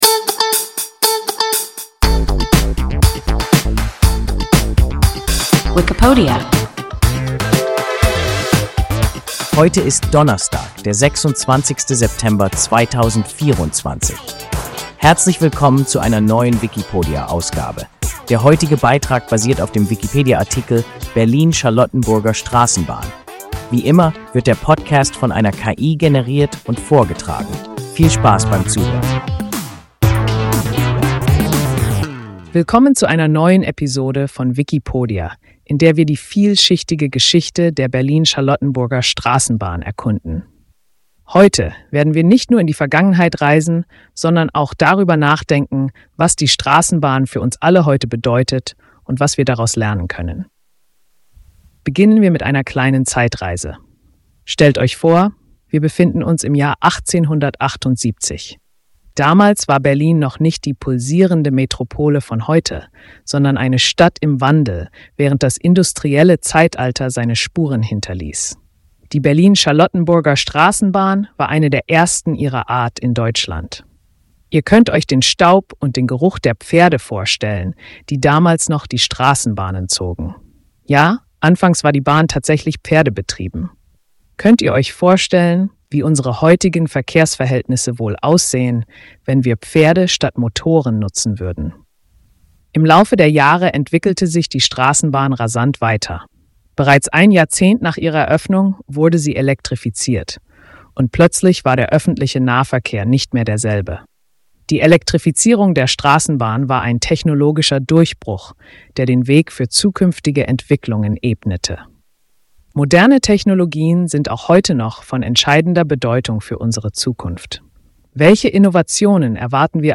Berlin-Charlottenburger Straßenbahn – WIKIPODIA – ein KI Podcast